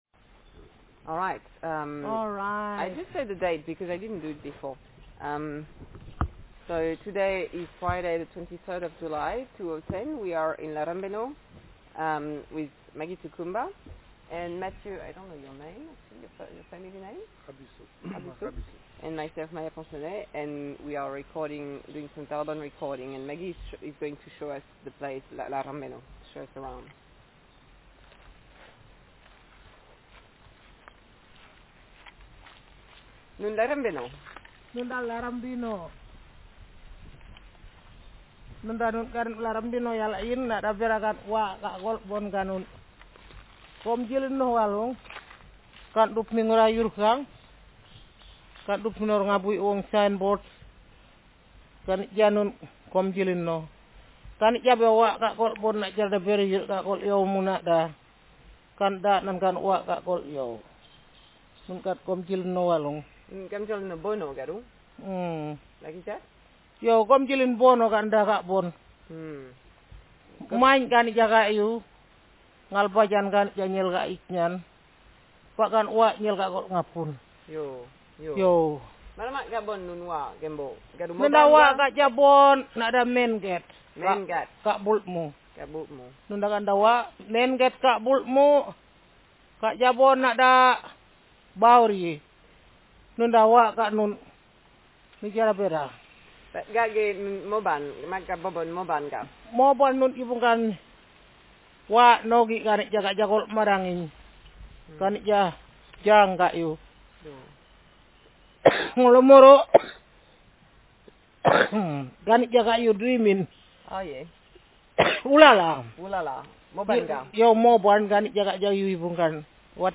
Speaker sexf
Text genrepersonal narrative